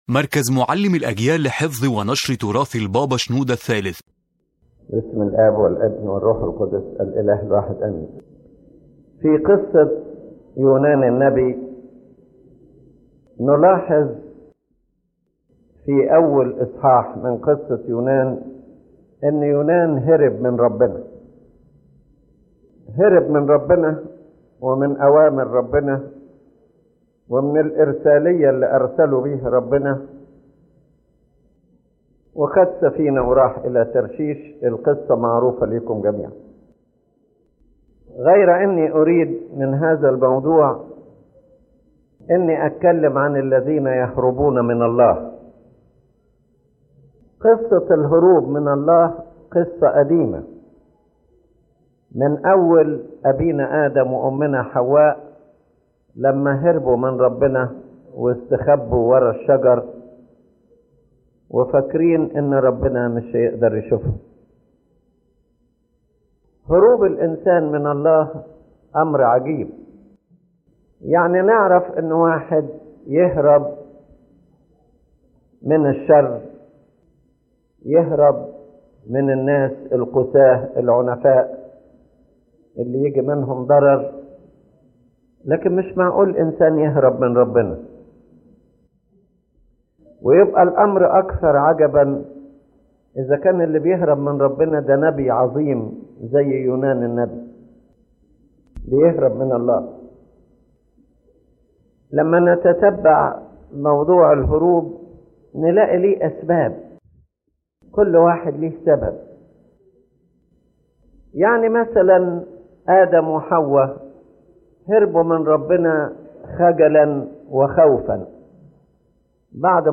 تتناول هذه المحاضرة ظاهرة الهروب من الله كما ظهرت في قصة يونان النبي، موضحة أن الهروب ليس حادثة فردية بل حالة روحية قديمة بدأت منذ سقوط الإنسان الأول.